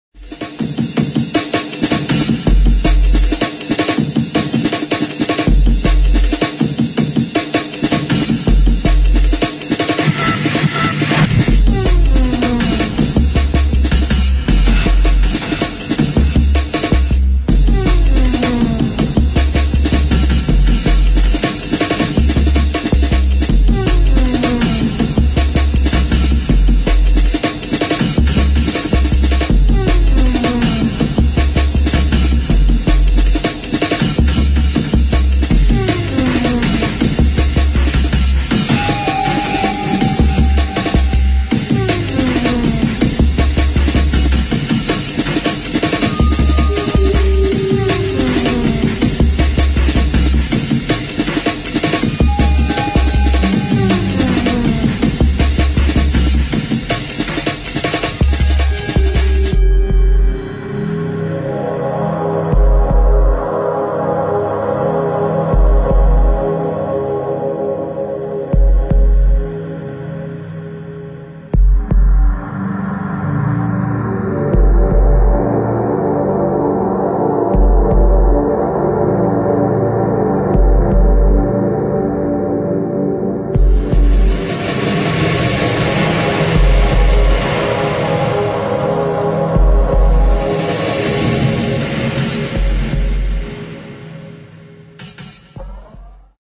[ DRUM'N'BASS | JUNGLE ]